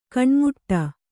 ♪ kaṇmuṭṭa